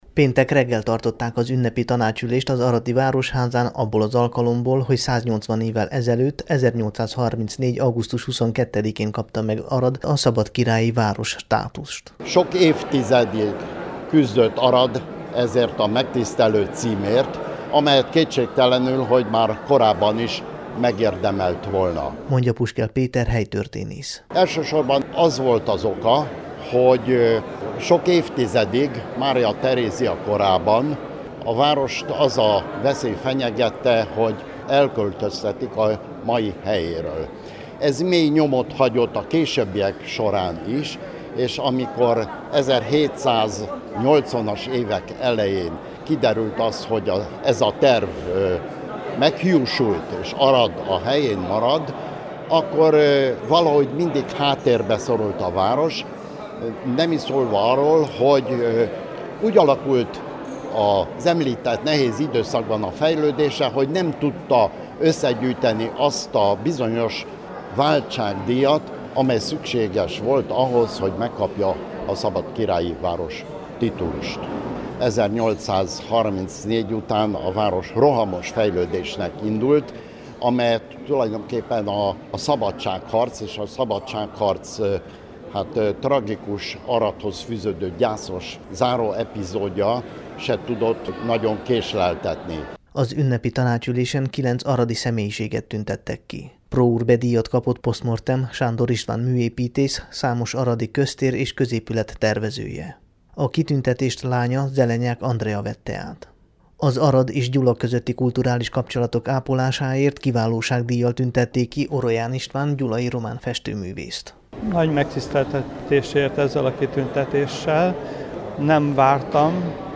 Kilenc aradi személyiséget tüntettek ki Pro Urbe-, Kiválóság- vagy Díszoklevéllel az aradi városi képviselőtestület péntek délelőtti ünnepi tanácsülésén, a vasárnapig tartó városnapok keretében.
összefoglalót a Temesvári Rádió számára